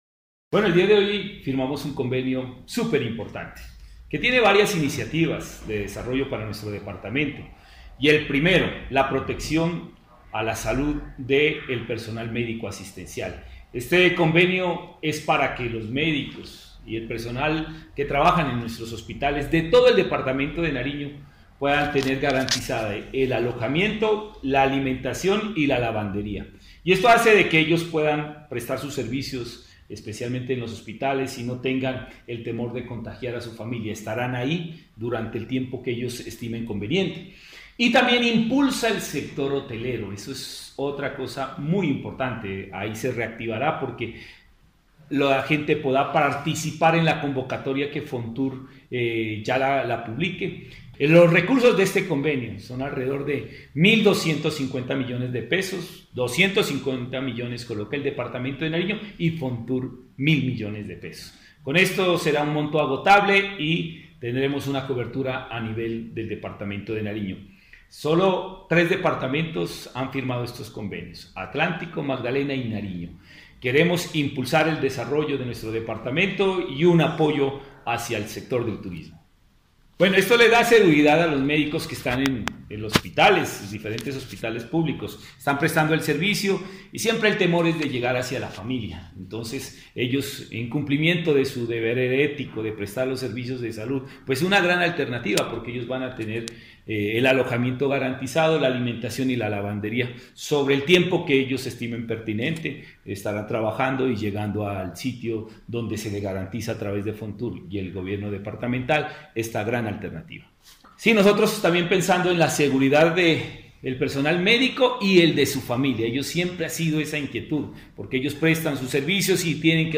El Gobernador de Nariño, Jhon Rojas, manifestó que una de las mayores preocupaciones del personal de salud es el temor de contagiar a su núcleo familiar.
Gobernador-Jhon-Rojas.mp3